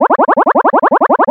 Sons et bruitages de jeux vidéos